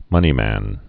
(mŭnē-măn)